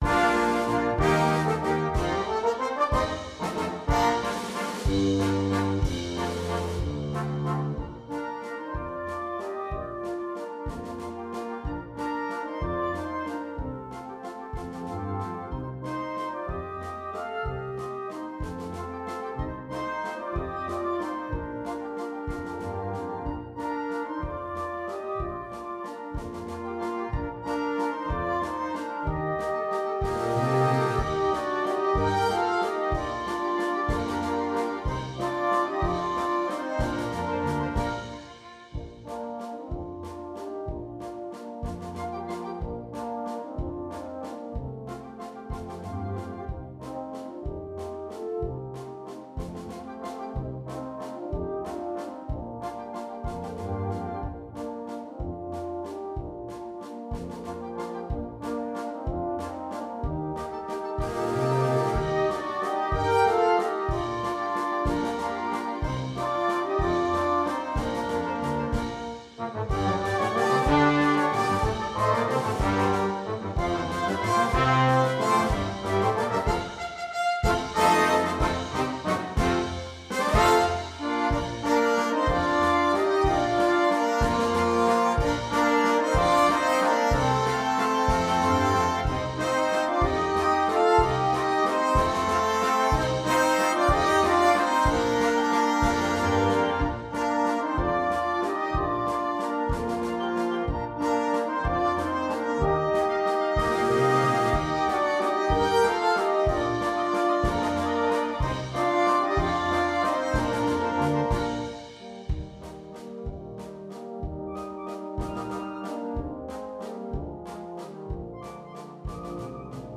Banda completa
Música ligera
audición